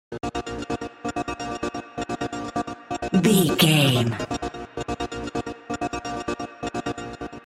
Dubstep Boom Stinger.
Epic / Action
Fast paced
Aeolian/Minor
B♭
aggressive
powerful
dark
driving
energetic
intense
drum machine
synthesiser
breakbeat
synth leads
synth bass